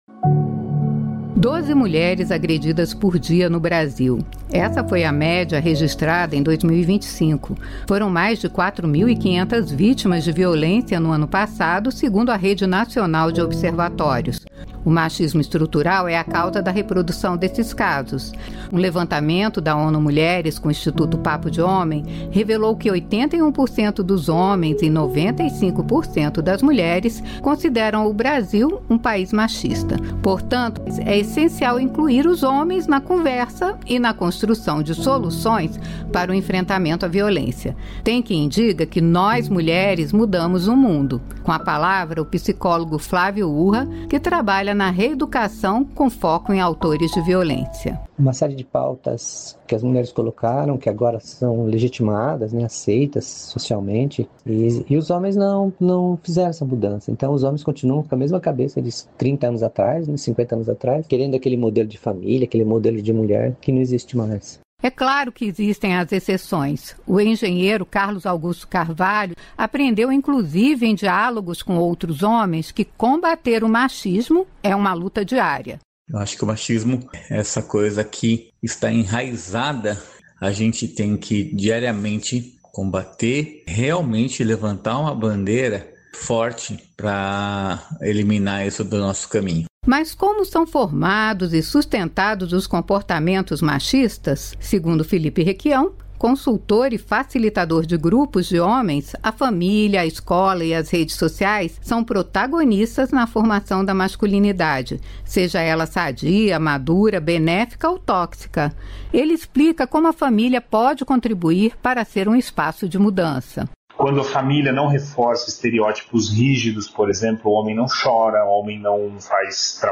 O machismo estrutural faz com que esses casos se repitam, na avaliação de especialistas entrevistados pela Rádio Nacional.